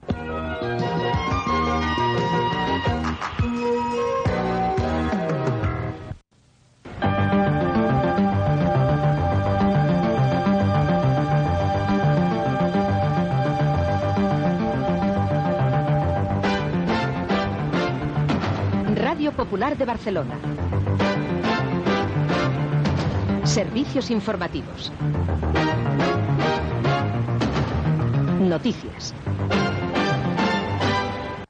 Sintonia de l'emissora i careta del butlletí de notícies.
FM